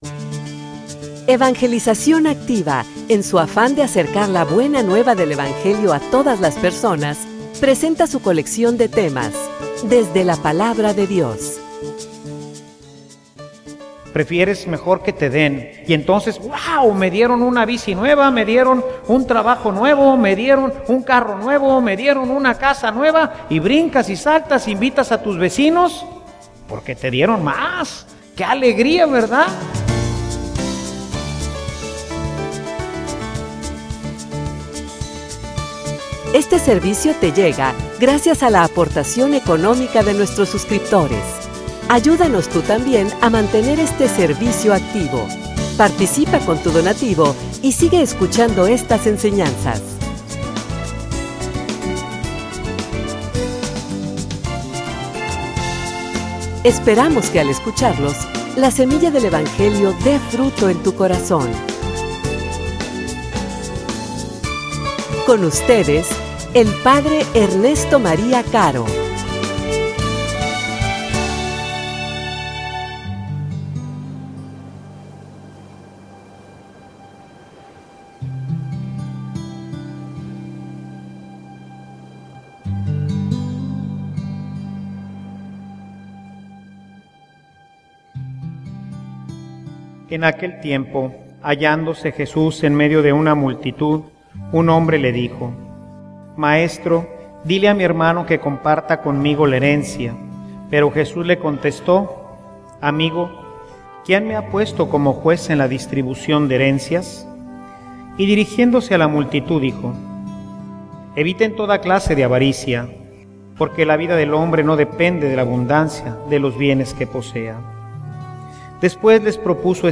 homilia_Liberados_para_compartir.mp3